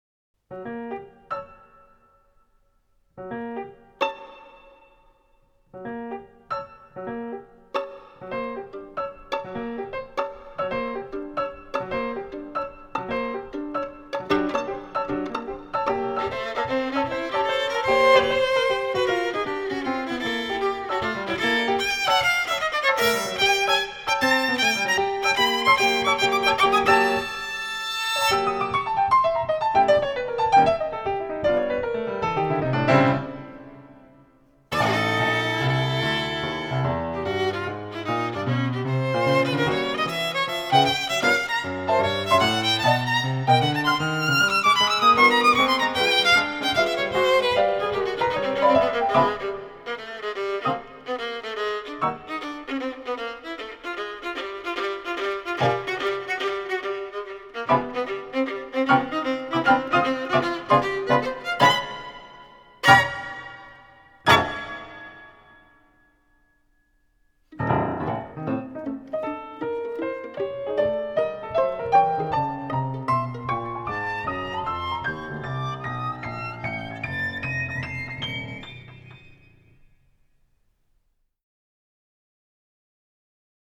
violin & piano (or cello & piano)